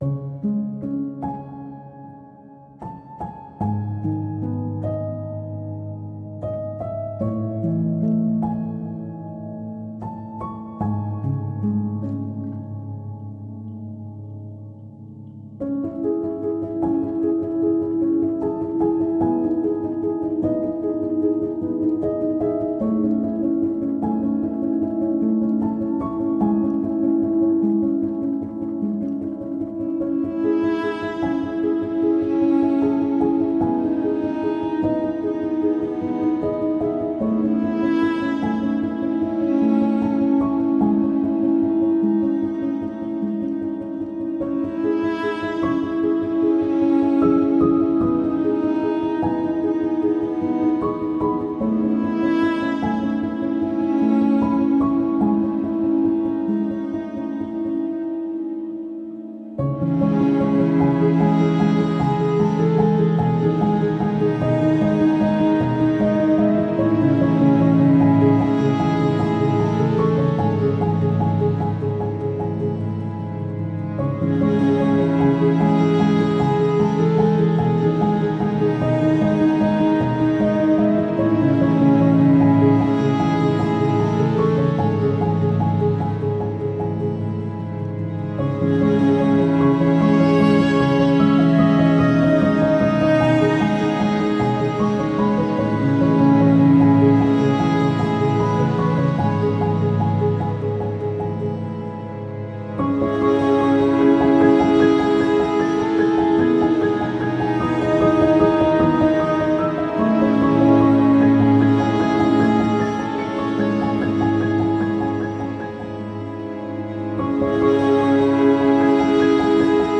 C instrumental
آهنگ پیانو